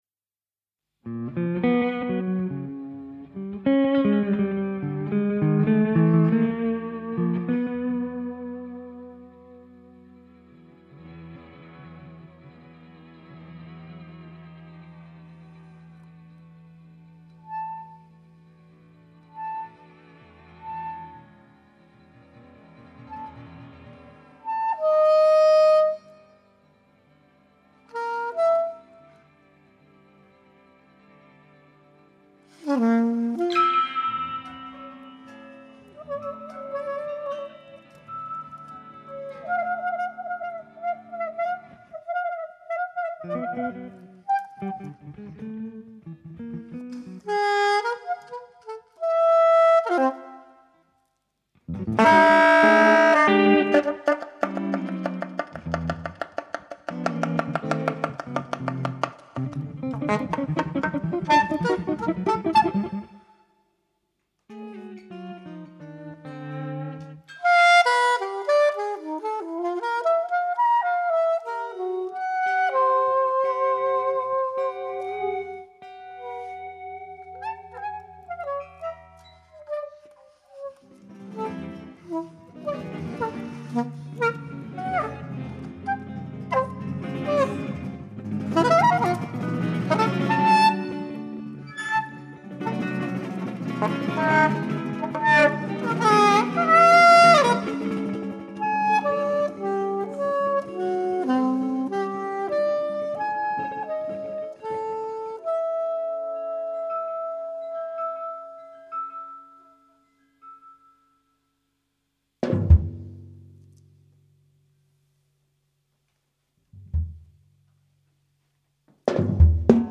Luogo esecuzioneDigitube studio, Mantova
GenereJazz